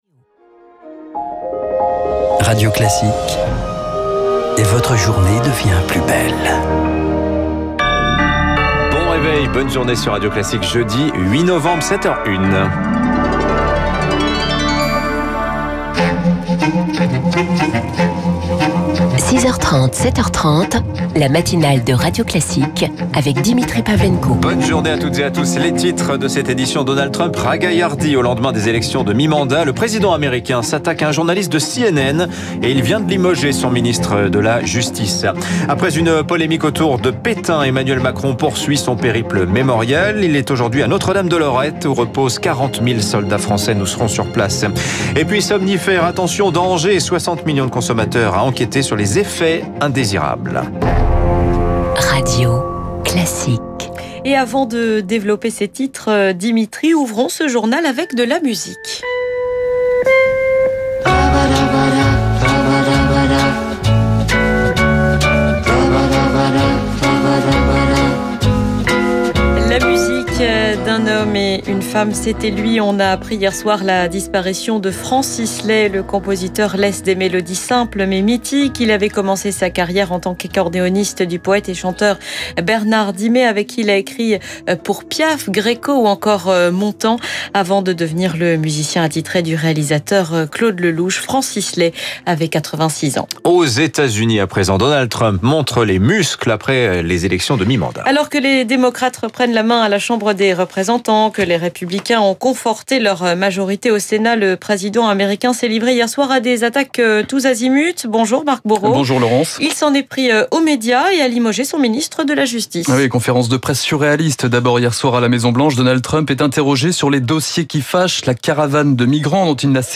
* Intervention à 7’30 minutes.